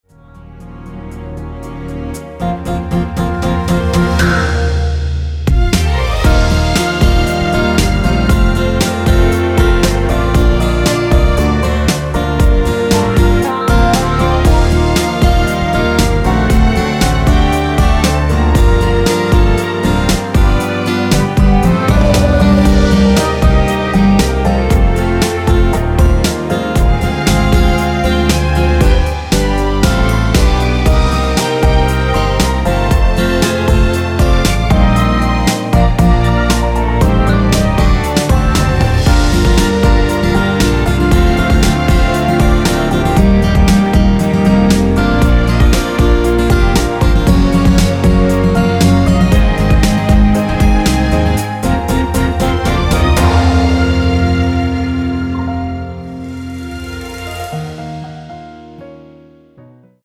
원키에서(-2)내린 (1절+후렴)으로 진행되는 MR입니다.
Gm
앞부분30초, 뒷부분30초씩 편집해서 올려 드리고 있습니다.
중간에 음이 끈어지고 다시 나오는 이유는